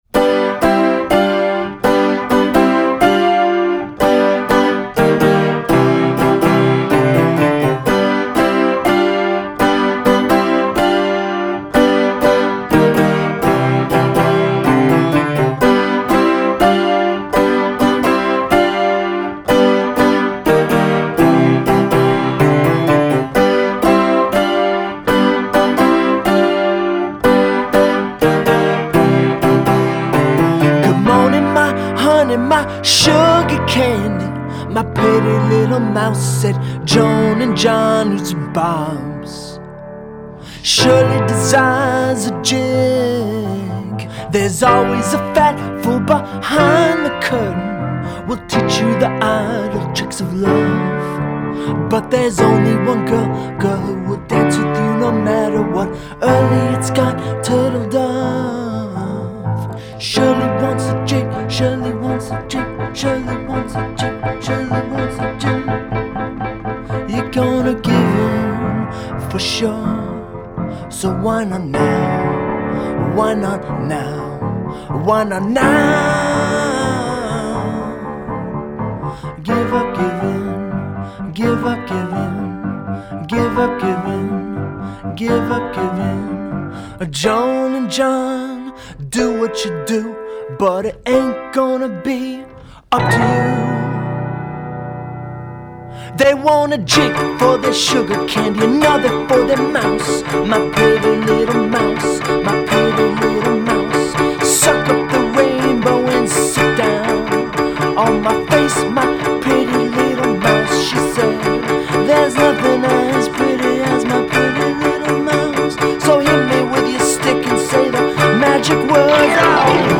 spotlights the piano and is out January 25.